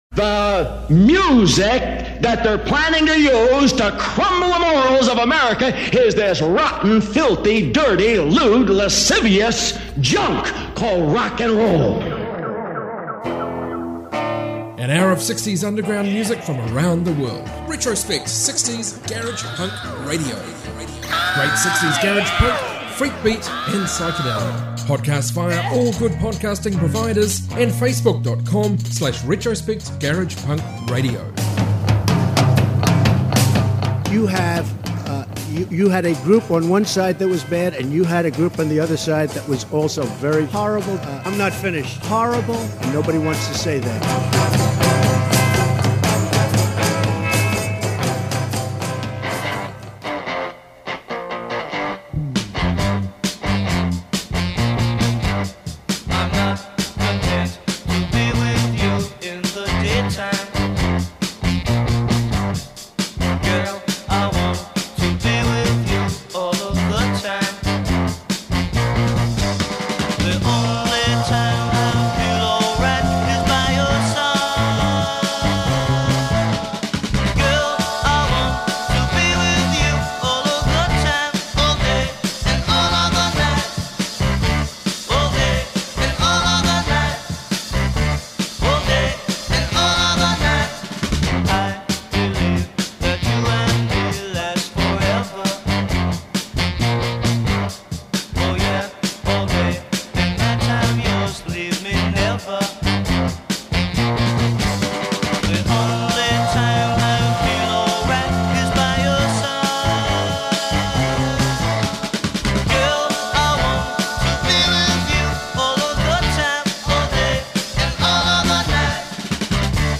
global 60s garage rock